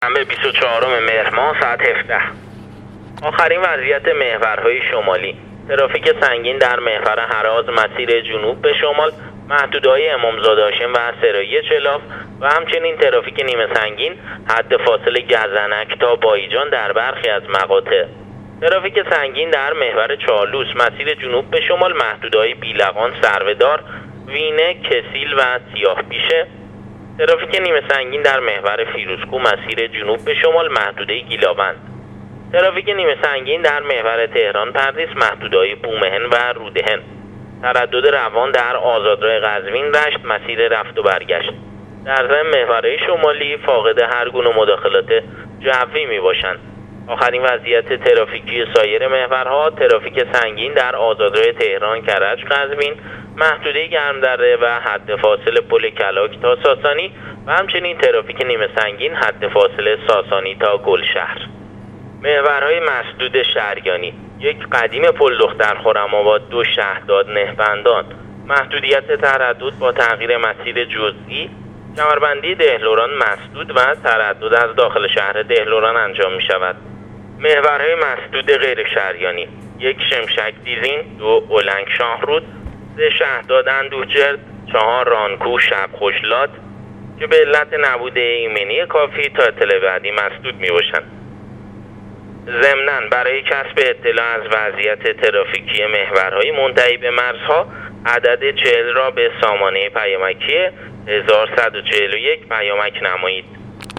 گزارش آخرین وضعیت ترافیکی و جوی جاده‌های کشور را از رادیو اینترنتی پایگاه خبری وزارت راه و شهرسازی بشنوید.